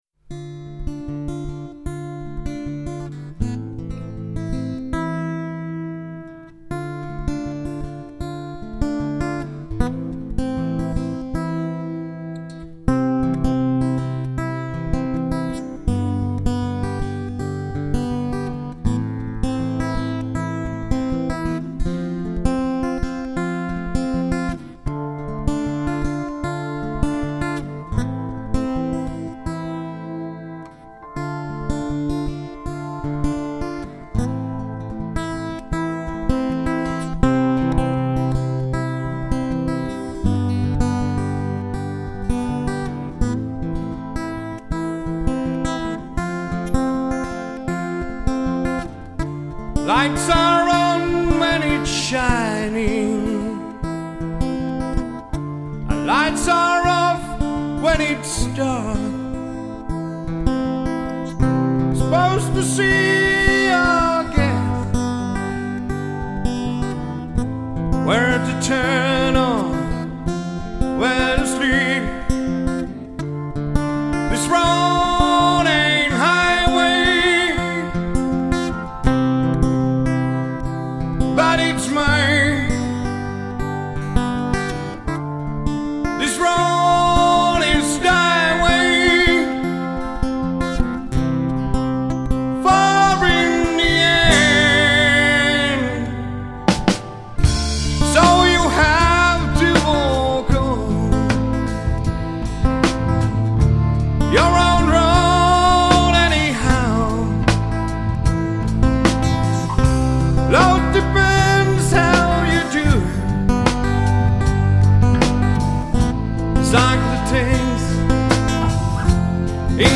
Äänitetty studiossa livenä 16 raidalle.
Laulajalla voimakas ja hyvä ääni.
basisti, laulaja, biisintekijä